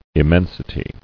[im·men·si·ty]